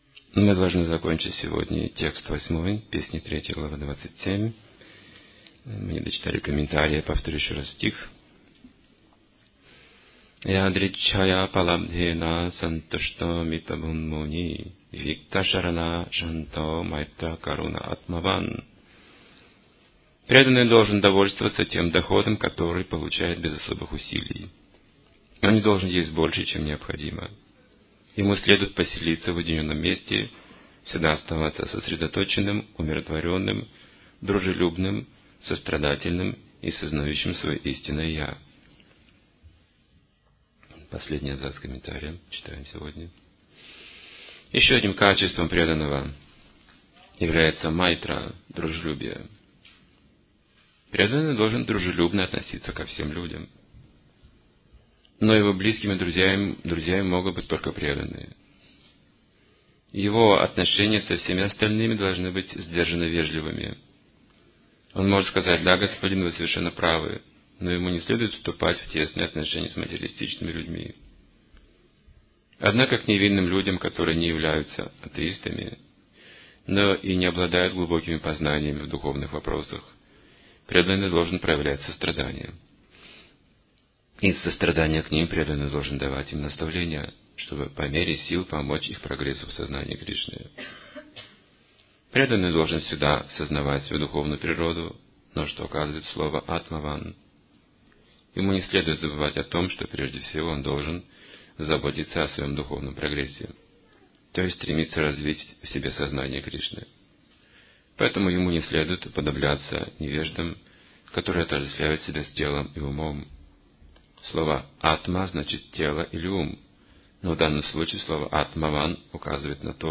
Темы, затронутые в лекции: